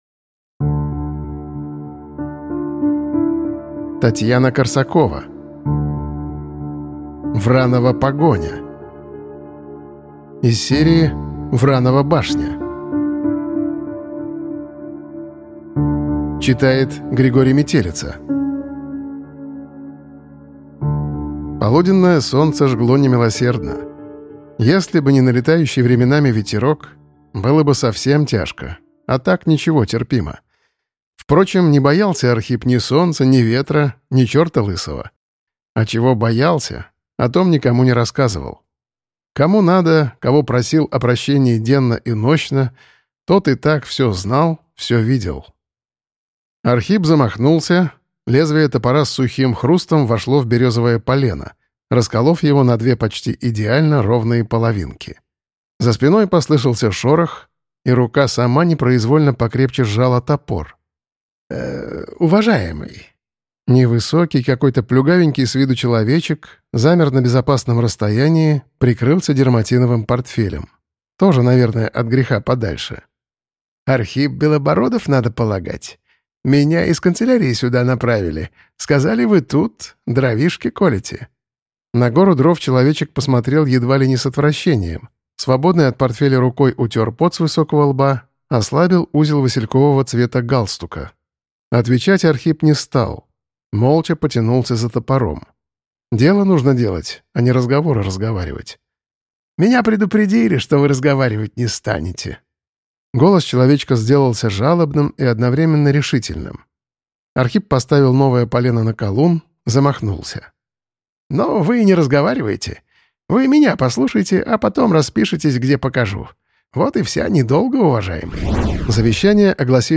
Аудиокнига Вранова погоня | Библиотека аудиокниг